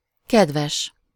Ääntäminen
IPA: /ˈkɛdvɛʃ/